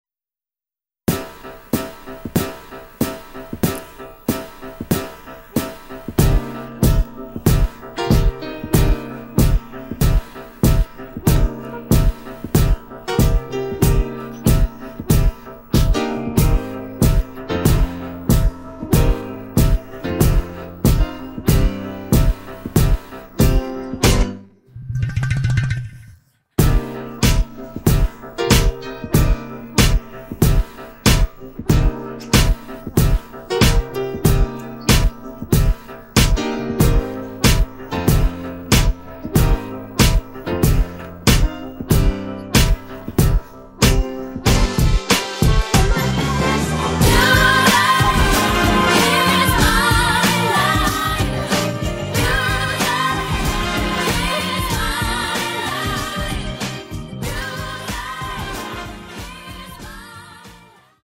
음정 원키 (코러스
장르 가요 구분